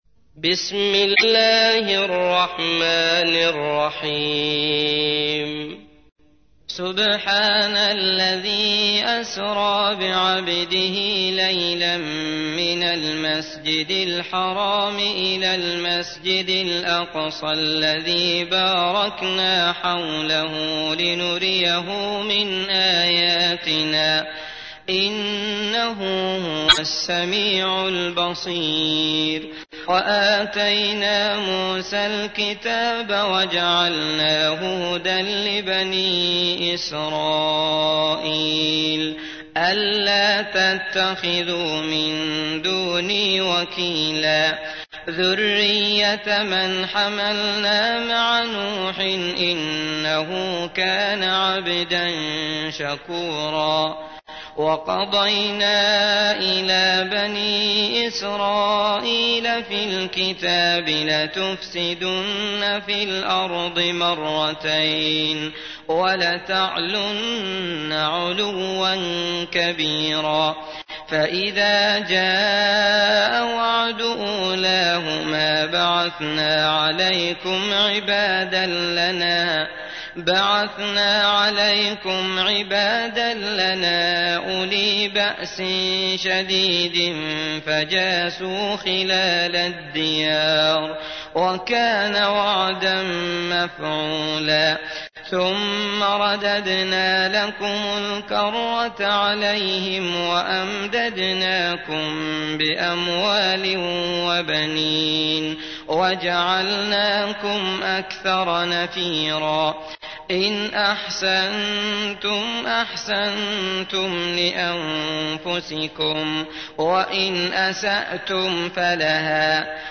تحميل : 17. سورة الإسراء / القارئ عبد الله المطرود / القرآن الكريم / موقع يا حسين